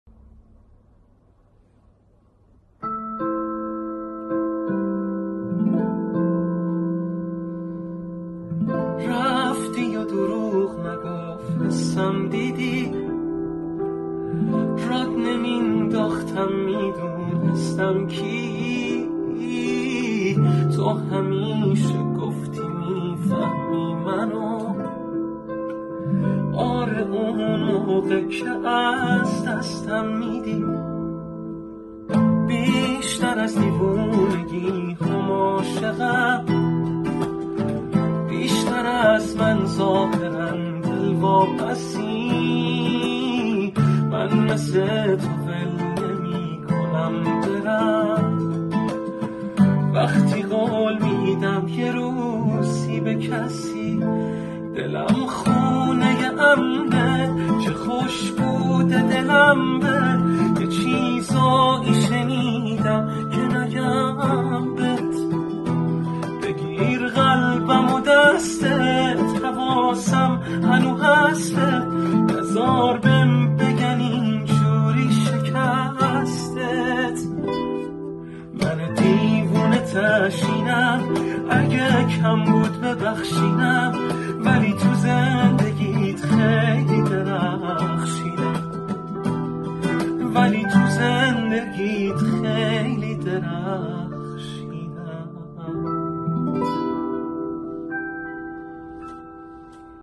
اجرای خانگی